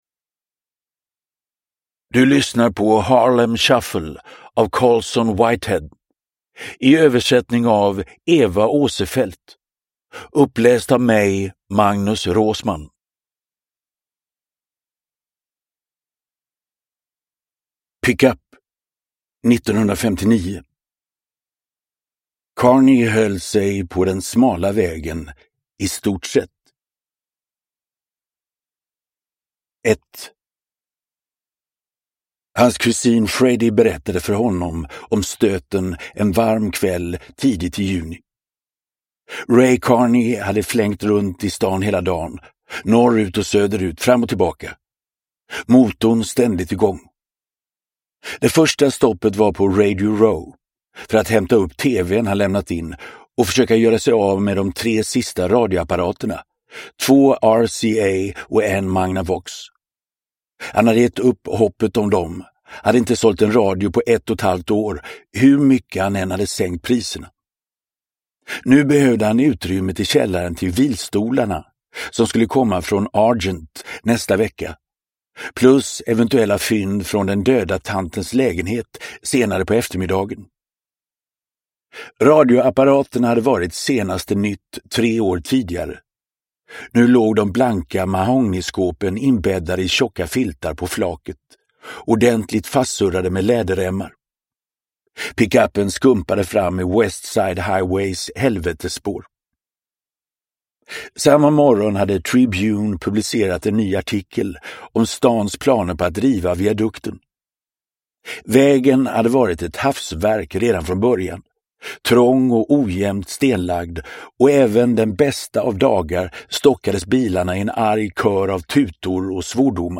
Harlem Shuffle – Ljudbok – Laddas ner
Uppläsare: Magnus Roosmann